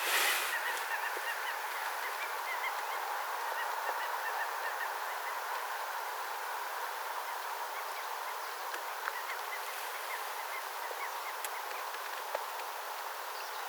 tupsu-uikun pikkulokkimaista ääntelyä
tupsu-uikun_pikkulokkimaista_aantelya.mp3